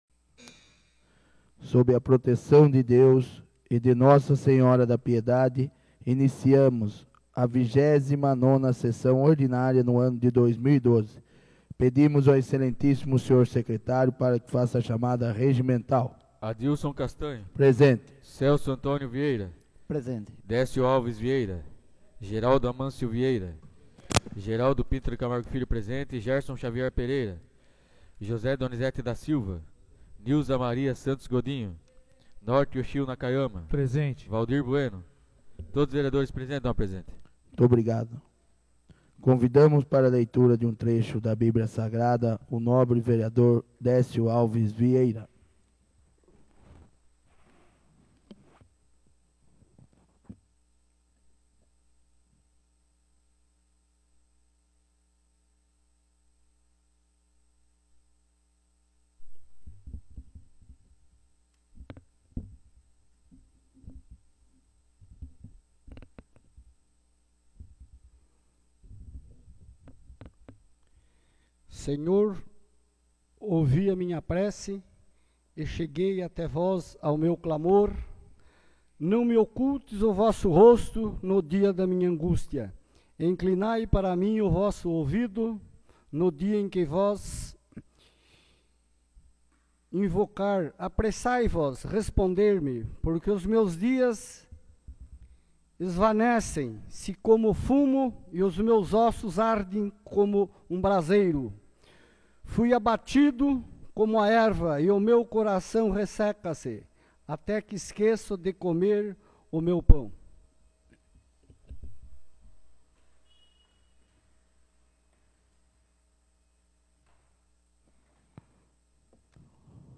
29ª Sessão Ordinária de 2012 — Câmara Municipal de Piedade